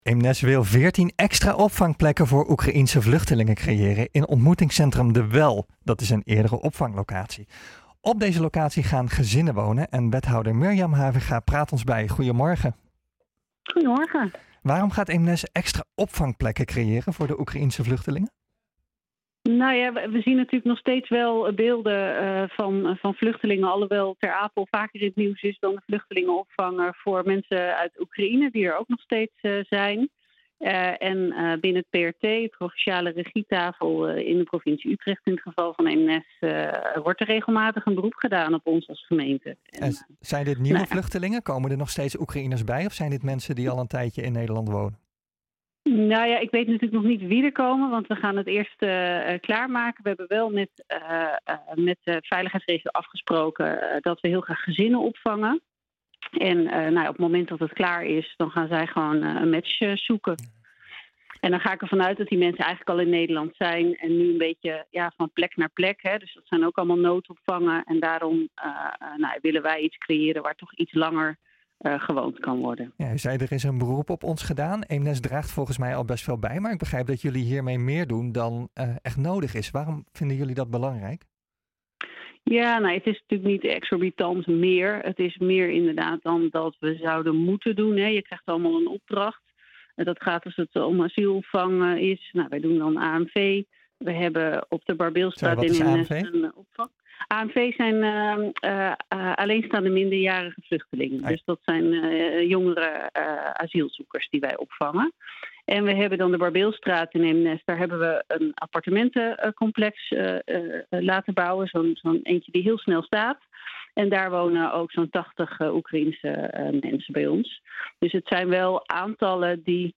Eemnes wil veertien extra opvangplekken voor Oekraïense vluchtelingen creëren in ontmoetingscentrum De Wel, een eerdere opvanglocatie. Op deze locatie gaan gezinnen wonen. Wethouder Mirjam Havinga praat ons bij.